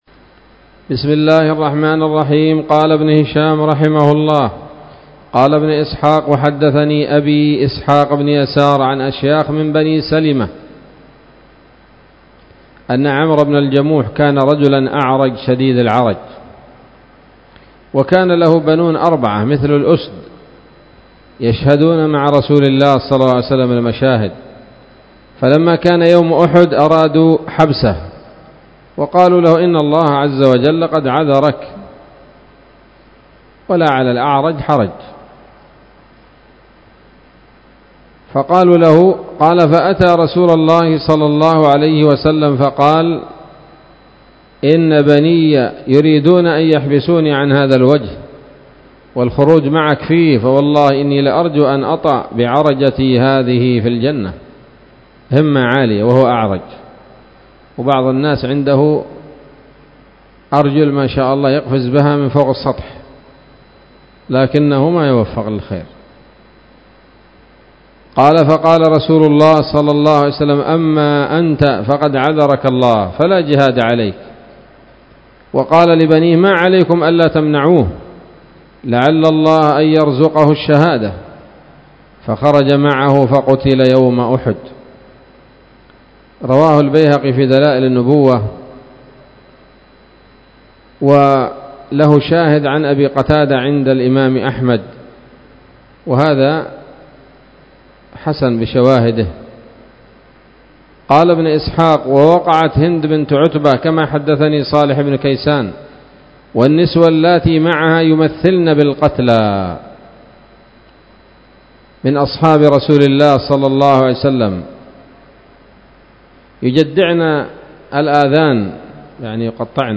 الدرس الرابع والستون بعد المائة من التعليق على كتاب السيرة النبوية لابن هشام